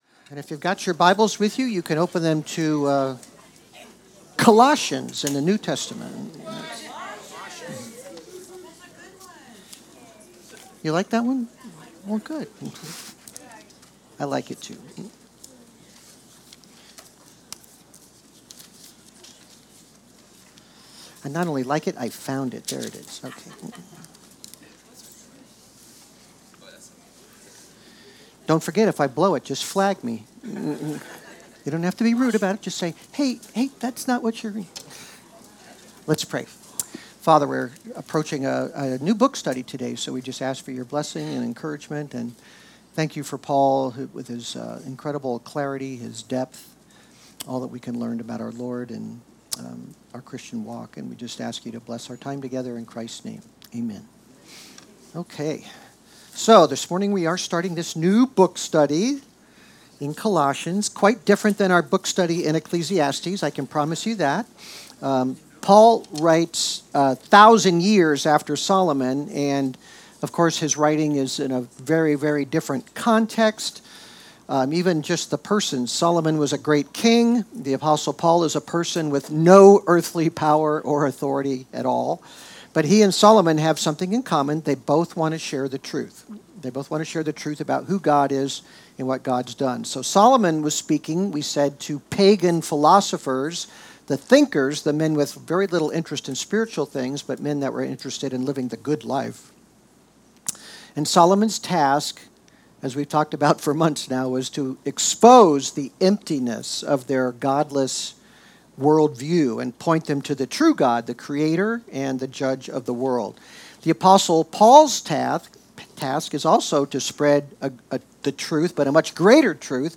Worship Series Colossians Book Colossians Watch Listen Save Colossians 1:1-6 What is the key component of the Gospel message that was preached throughout the ancient world?